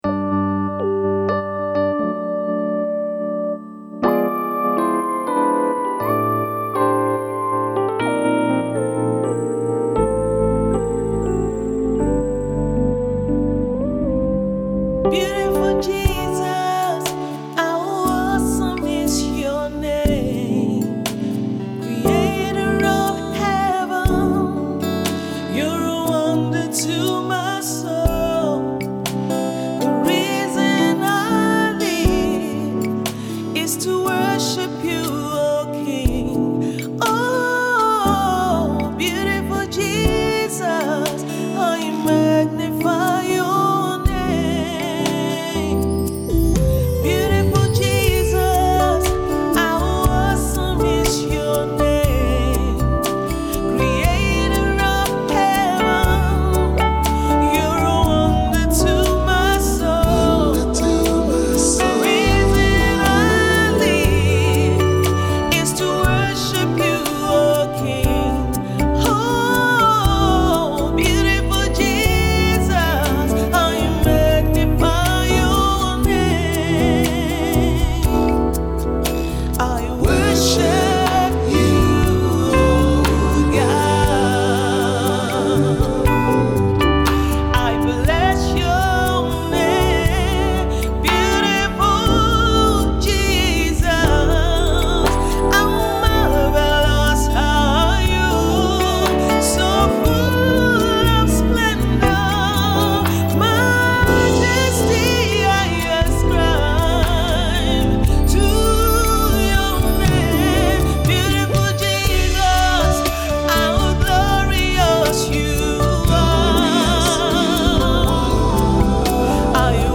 ” a heartfelt worship piece.